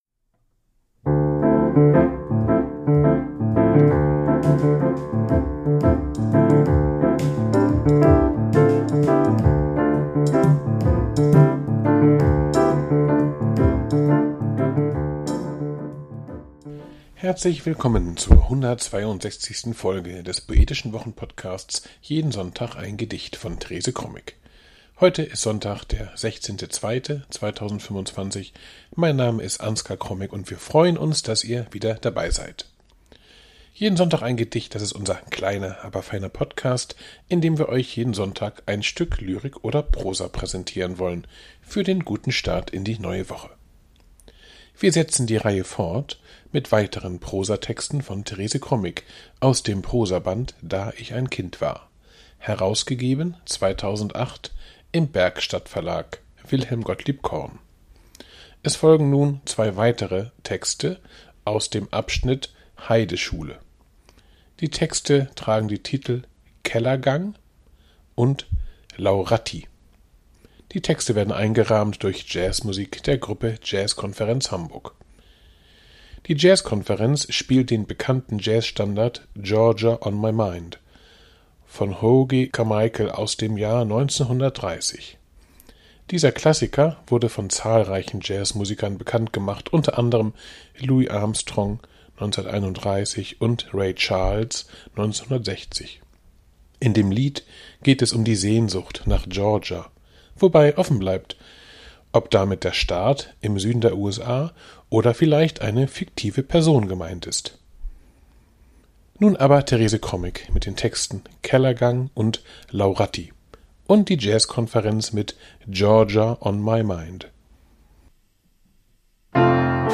Die Jazzkonferenz spielt den bekannten Jazz-Standard "Georgia on my